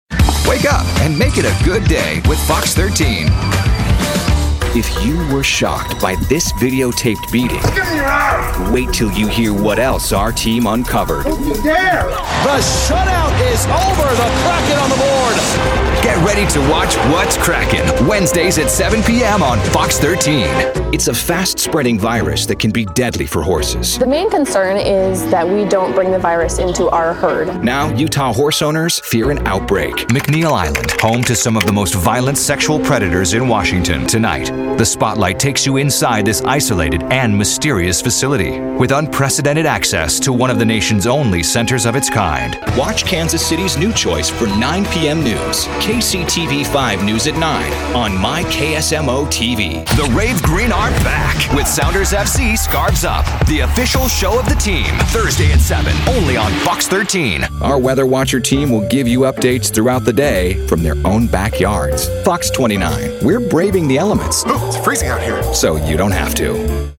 TV Affiliate Demo
Local News · Station Promo · Affiliate · Regional
tv-affiliate-demo.mp3